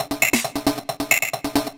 DS 135-BPM D9.wav